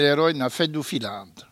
Maraîchin
Patois
Catégorie Locution